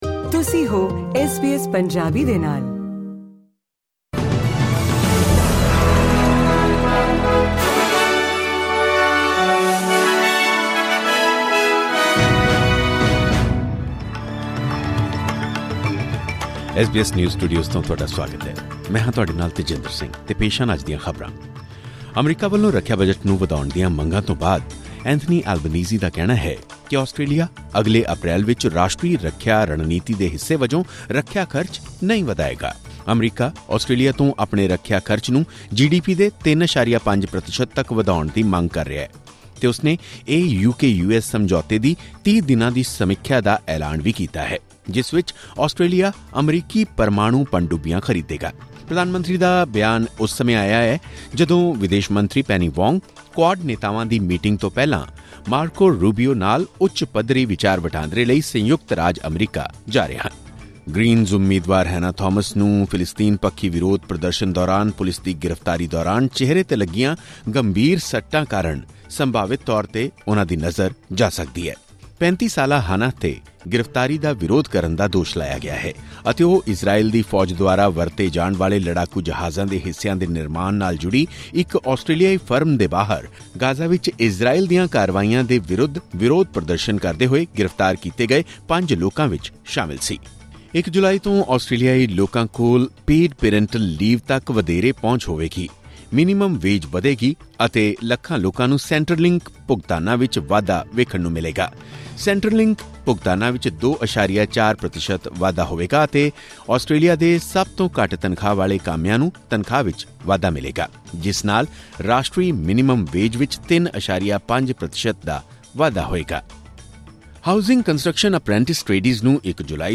ਖਬਰਨਾਮਾ: ਐਲਬਨੀਜ਼ੀ ਨੇ ਇੱਕ ਵਾਰ ਫਿਰ ਕਿਹਾ ਕਿ ਰਾਸ਼ਟਰੀ ਰੱਖਿਆ ਰਣਨੀਤੀ ਦੇ ਹਿੱਸੇ ਵਜੋਂ ਰੱਖਿਆ ਖਰਚ ਨਹੀਂ ਵਧਾਏਗਾ ਆਸਟ੍ਰੇਲੀਆ